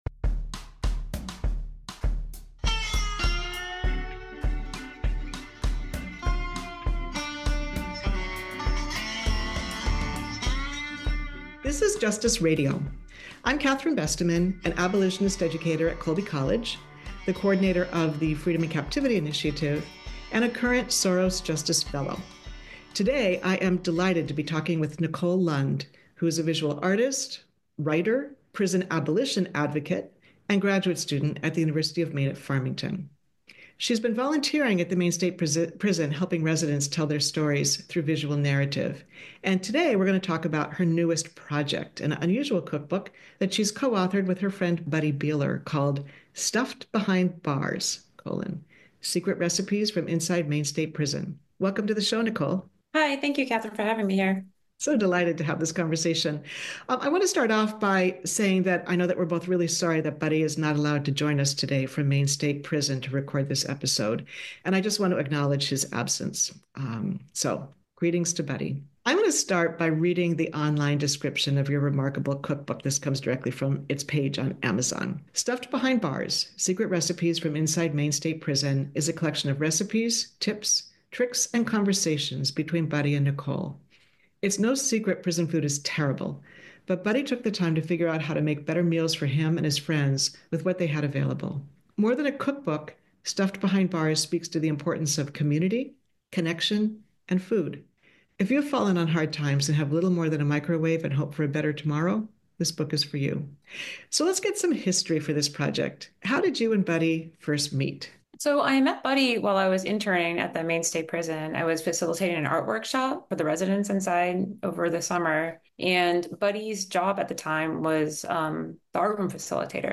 Maine Local News and Public Affairs Archives